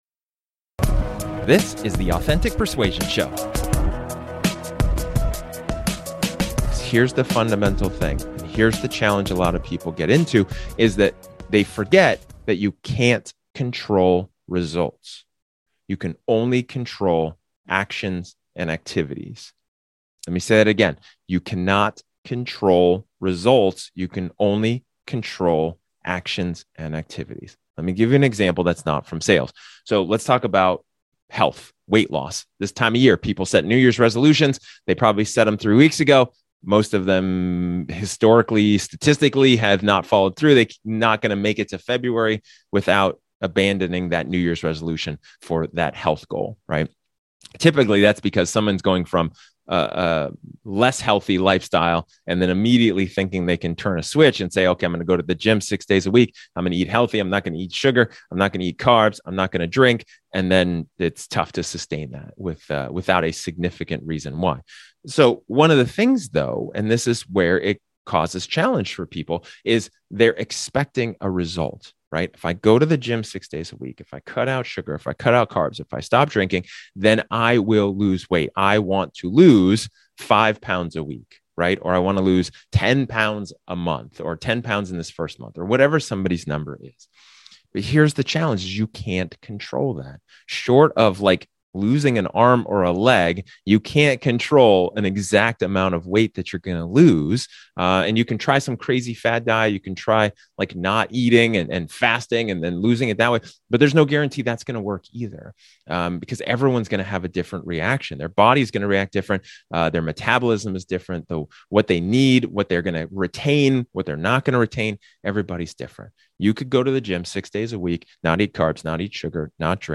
In this solo episode, I talk about finding the right focus.